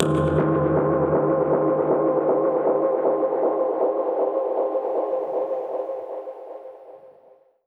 Index of /musicradar/dub-percussion-samples/125bpm
DPFX_PercHit_A_125-10.wav